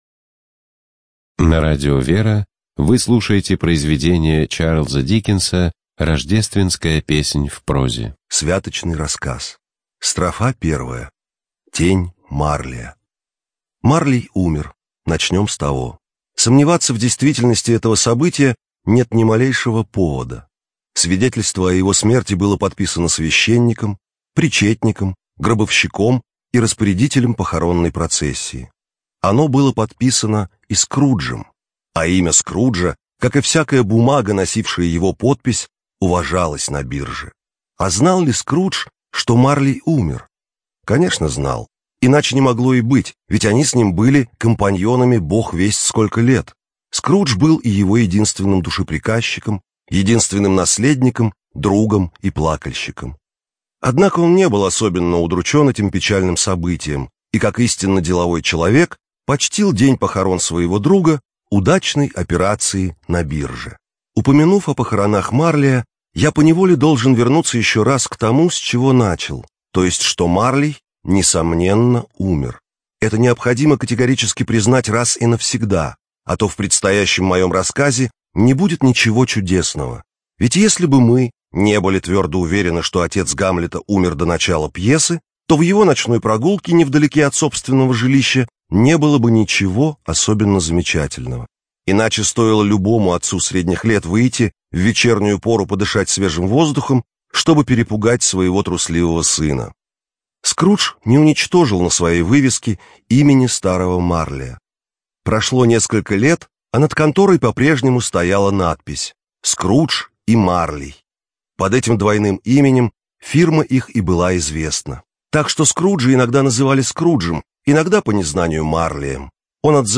ЖанрКлассическая проза
Студия звукозаписиРадио Вера